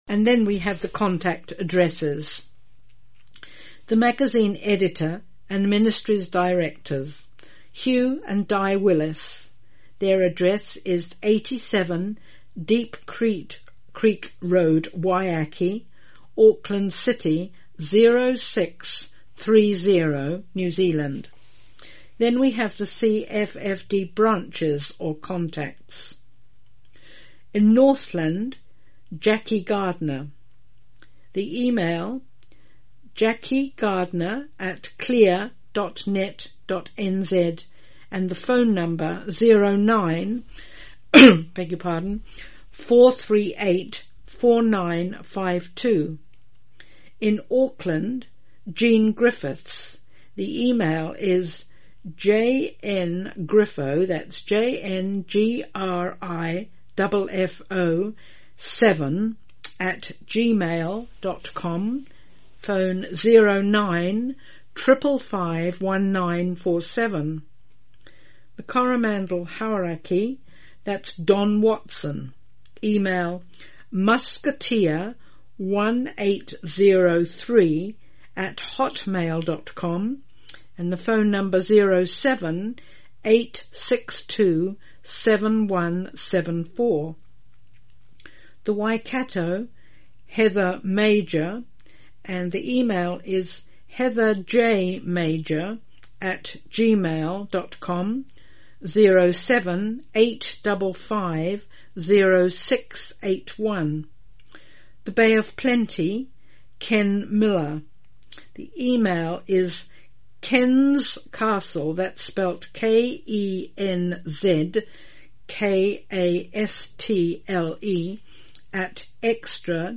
Genre: Speech..Released: 2015.